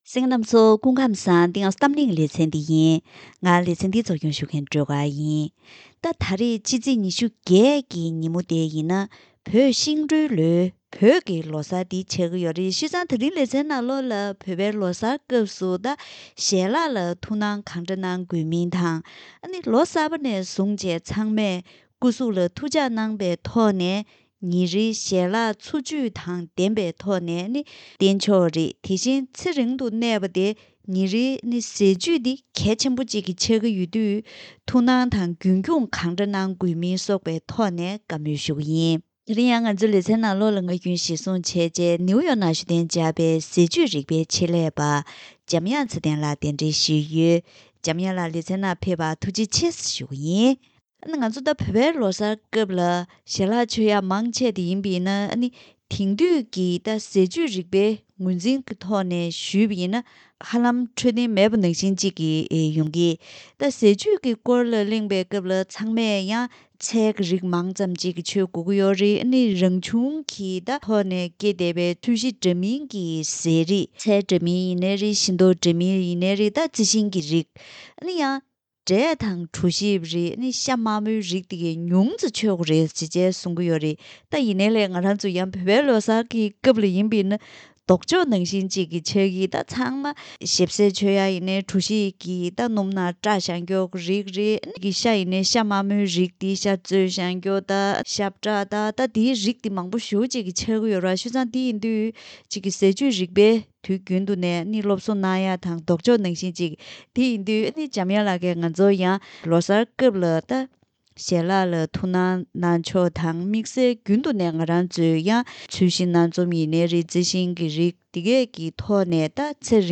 ཐུགས་སྣང་དང་རྒྱུན་འཁྱོངས་གང་འདྲ་དགོས་མིན་སོགས་ཀྱི་ཐོག་བཀའ་མོལ་ཞུས་པ་ཞིག་གསན་རོགས་གནང་།